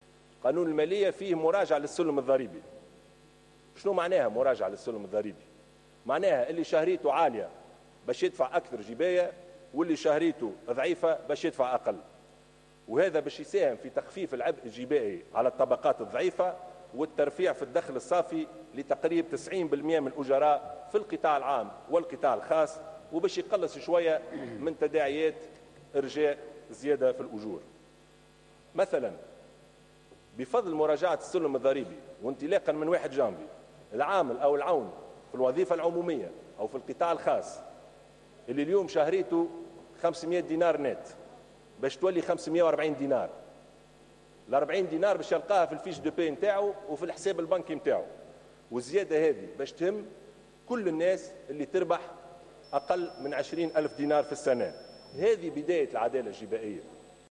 قال رئيس الحكومة يوسف الشاهد، خلال جلسة عامة انعقدت اليوم بمجلس نواب الشعب للنظر في مشروع قانون المالية لسنة 2017 إن مراجعة السلم الضريبي سيساهم في تخفيف العبء الجبائي على الطبقات الضعيفة.